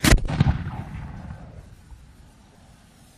Grenades | Sneak On The Lot